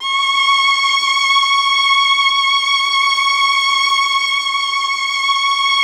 MELLOTRON.17.wav